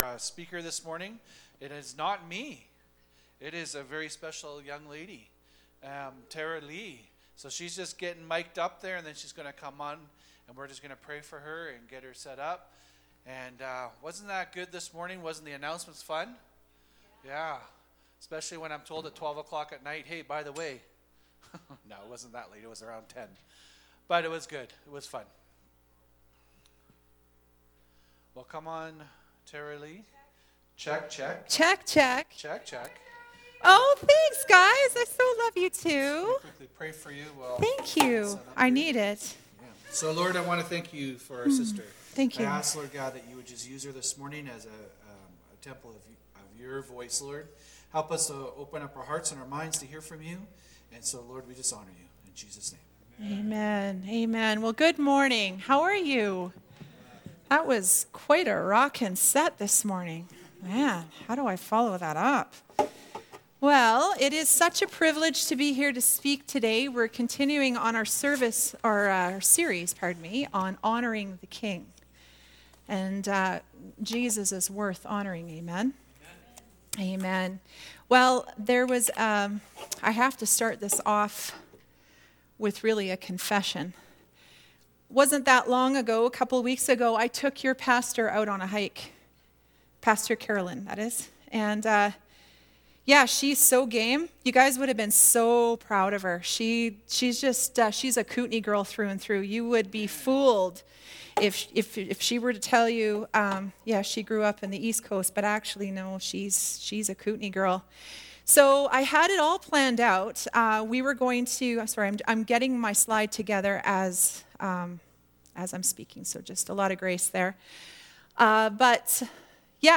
Passage: Leviticus 6:12-13 Service Type: Sunday Service